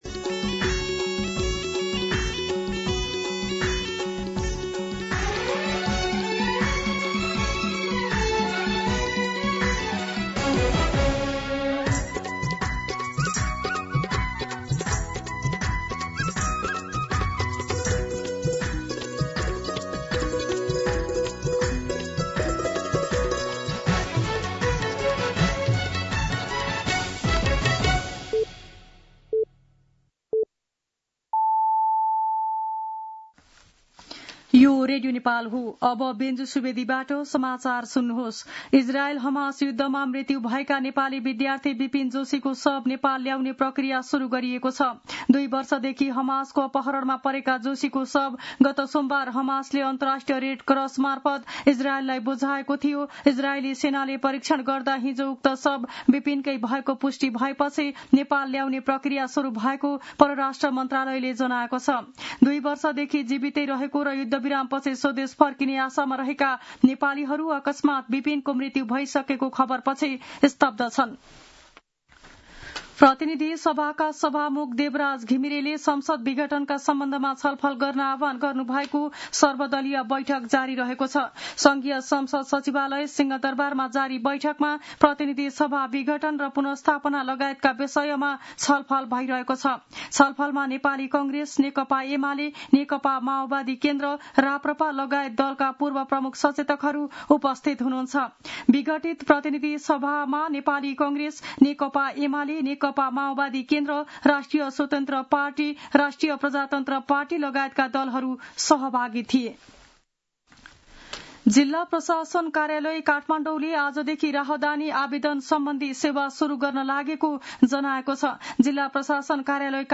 मध्यान्ह १२ बजेको नेपाली समाचार : २९ असोज , २०८२